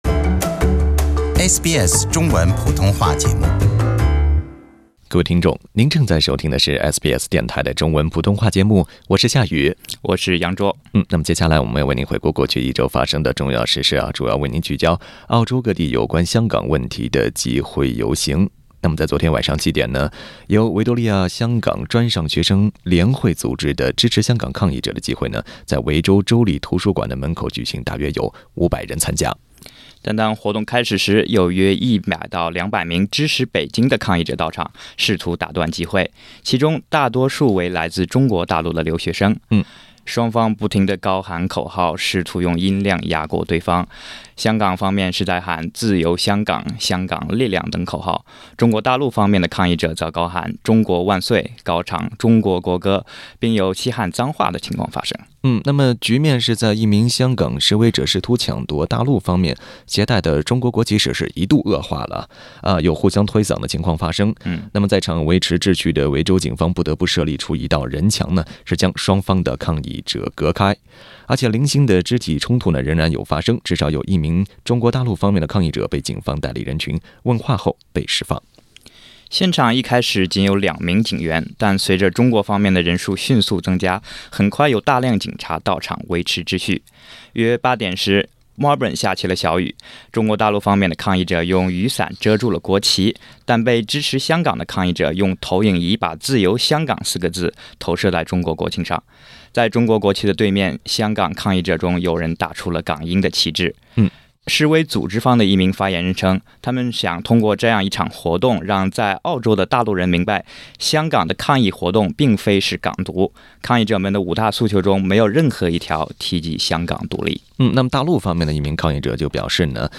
双方产生摩擦，不得不靠警方维持秩序。详情请听SBS中文普通话广播为您带来的现场报道。